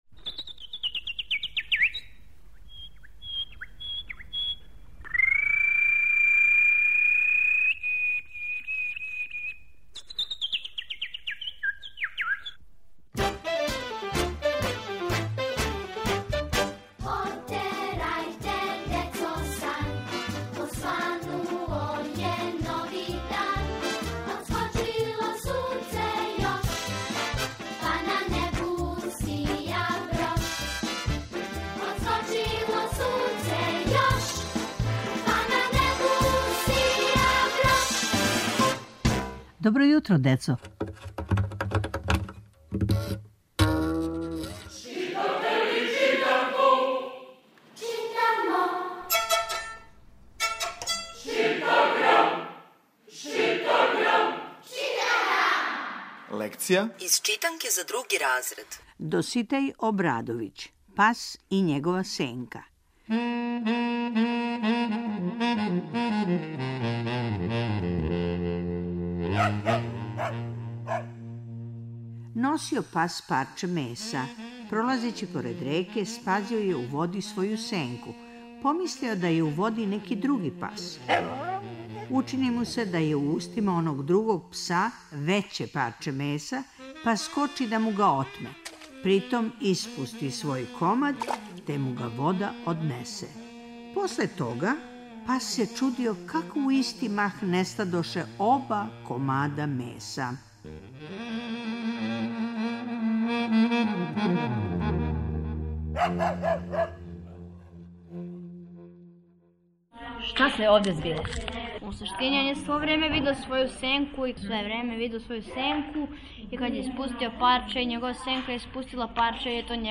Сваког понедељка у емисији Добро јутро, децо - ЧИТАГРАМ: Читанка за слушање. Ове недеље - други разред, лекција: "Пас и његова сенка", прича Доситеја Обрадовића.